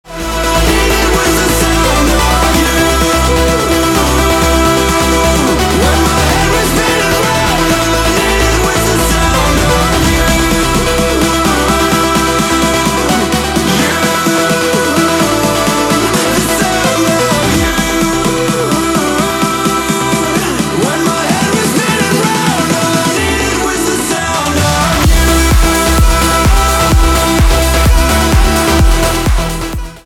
танцевальные
битовые , басы , качающие , нарастающие